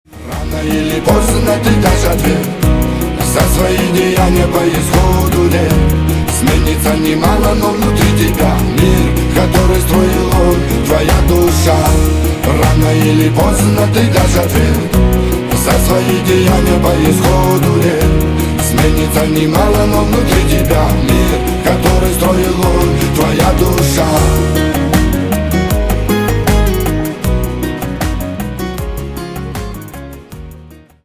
на русском на девушку про любовь восточные